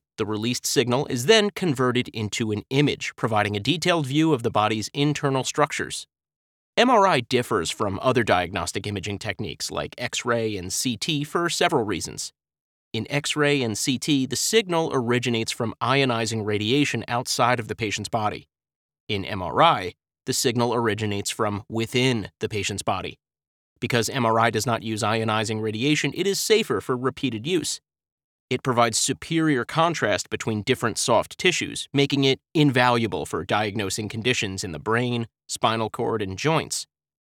eLearning - MRI
American (Generic and Regional)
Middle Aged
eLearning demo conversational MRI.mp3